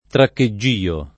[ trakke JJ& o ]